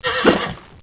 Heroes3_-_War_Unicorn_-_AttackSound.ogg